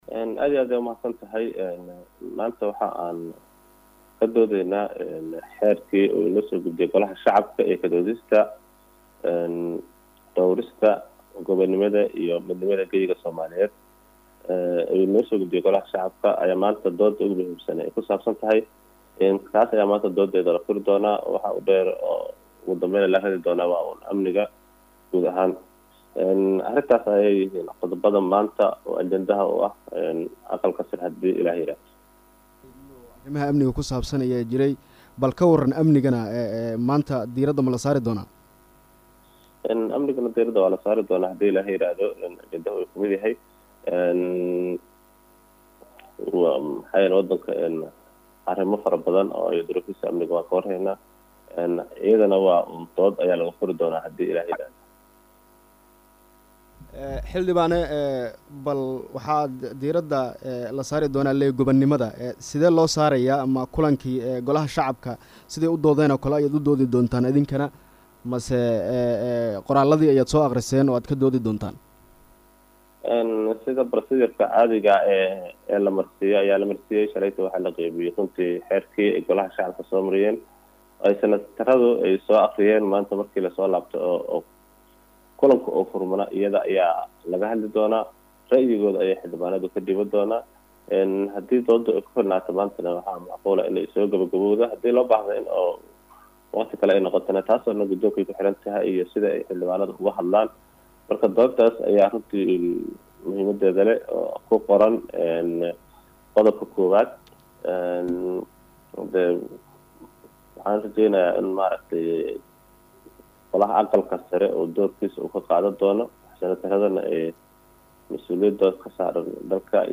Xildhibaan Maxamed Aamiin oo ka tirsan Xildhibaanada Aqalka sare ee Dalka oo la hadlay Radio Muqdisho ayaa faah -faahin ka bixiyay kulanka Aqalka sare ee Dalku Maanta ku yeelanayaan Magaalada Muqdisho.